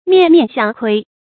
面面相窥 miàn miàn xiāng kuī
面面相窥发音